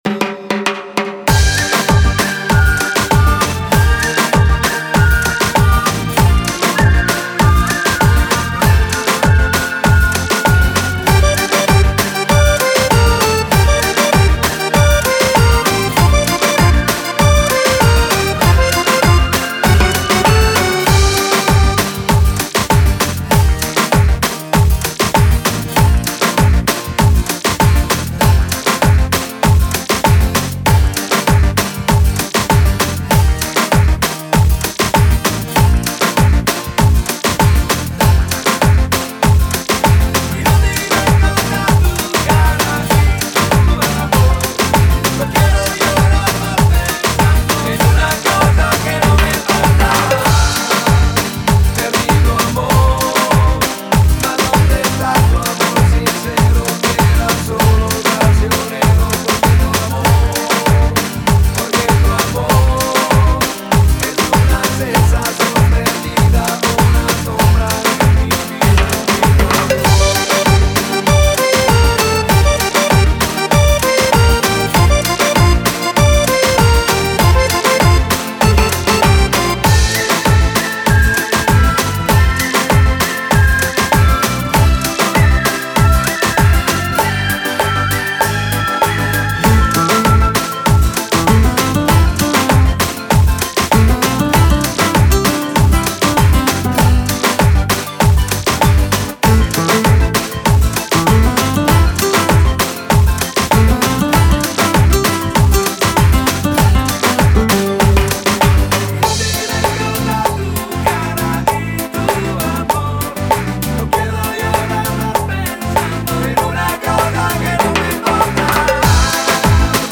una cumbia spanish